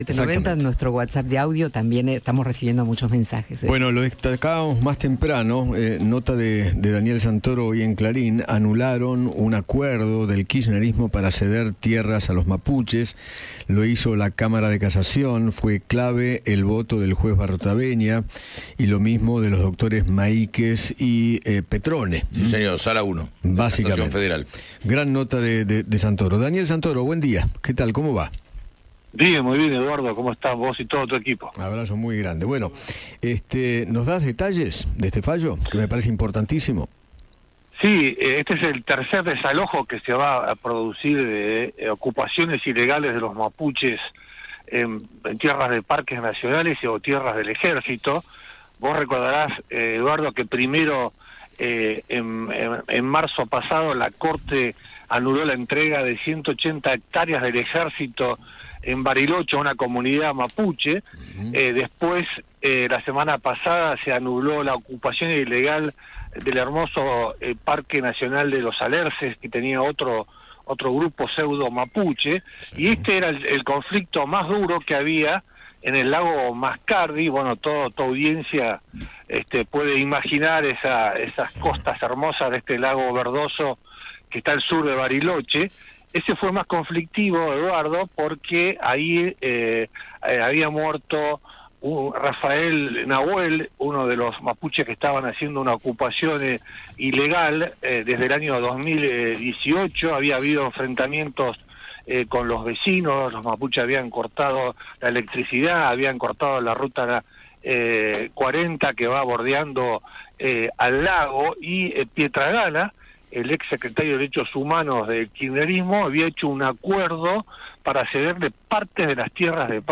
Eduardo Feinmann habló con el periodista Daniel Santoro sobre el nuevo fallo de la Justicia que ordena a una comunidad mapuche devolver los terrenos en la zona del Lago Mascardi en Bariloche.